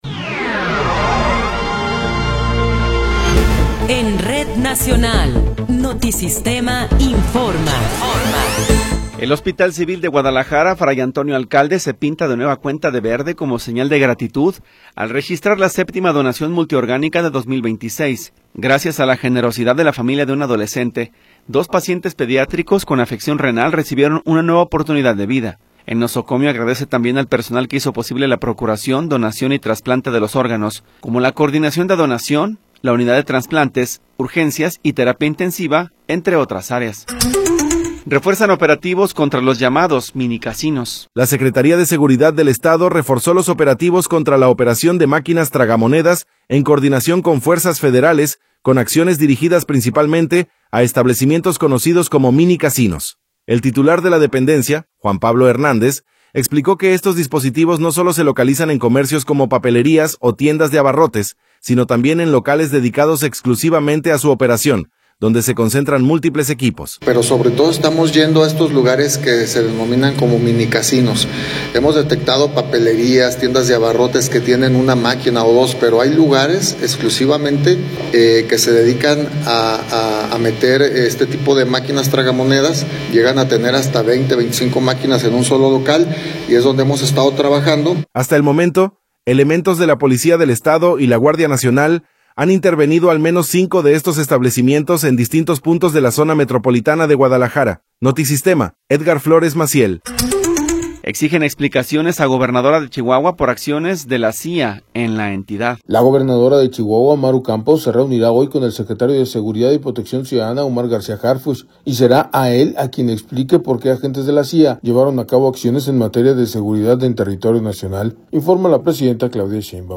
Noticiero 11 hrs. – 23 de Abril de 2026